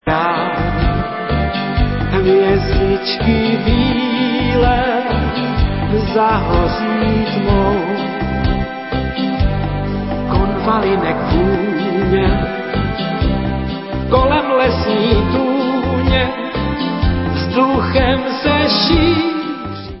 české pop-music